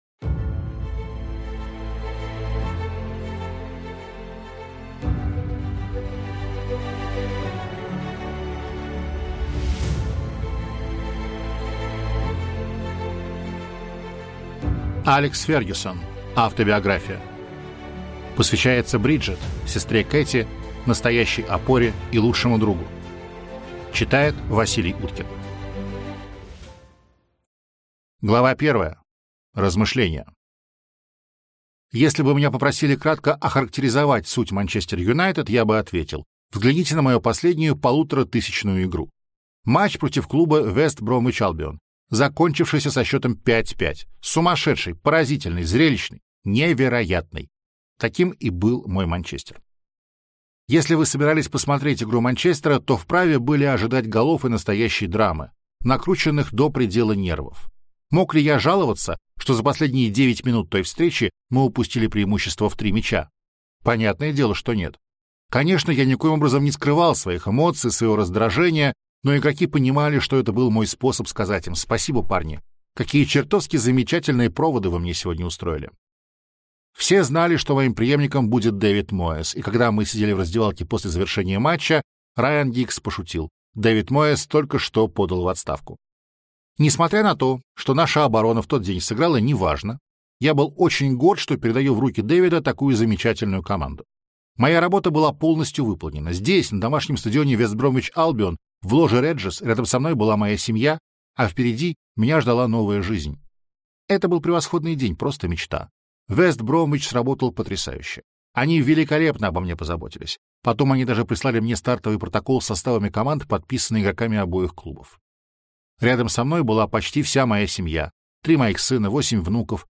Аудиокнига Автобиография | Библиотека аудиокниг